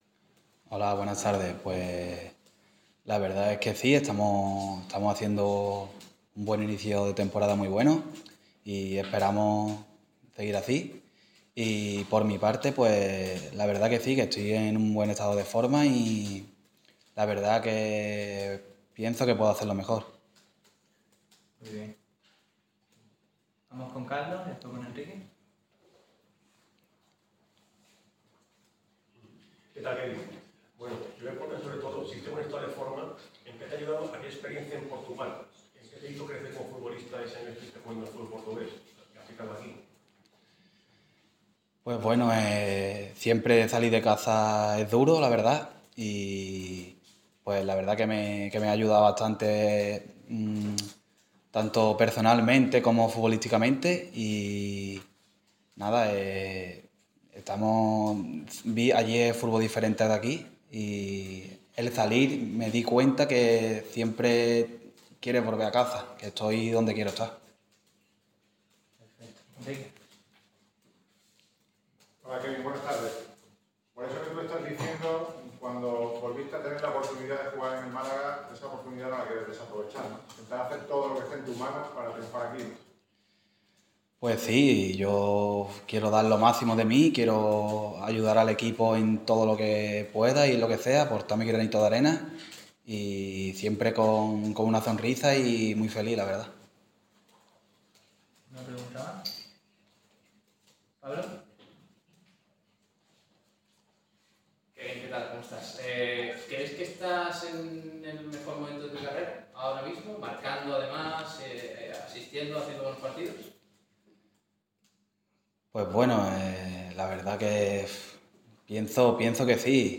El malagueño ha pasado por la sala de prensa de La Rosaleda para hablar ante los medios días antes del partido ante el Real Madrid Castilla.